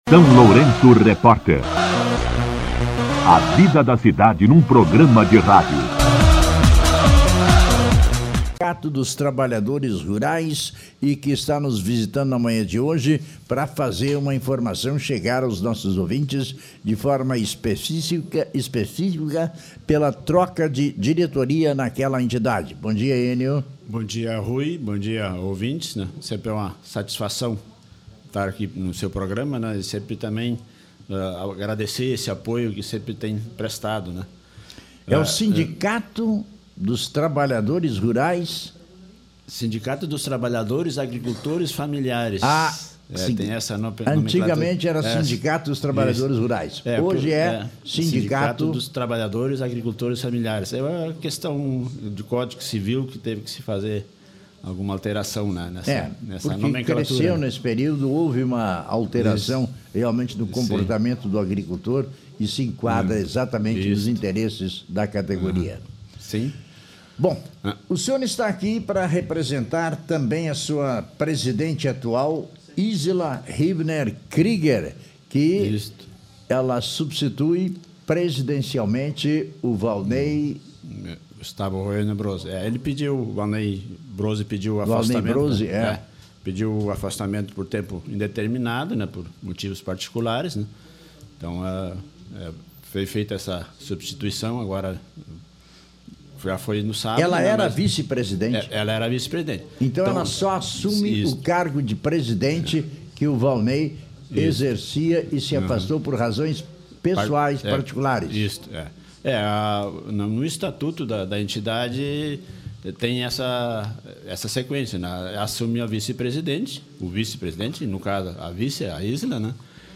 Entrevista com o secretário do sindicato